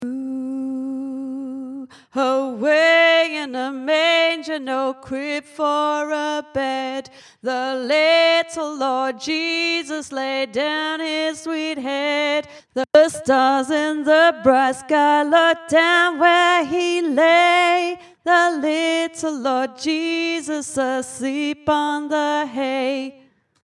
away-in-a-manger-tenor
away-in-a-manger-tenor.mp3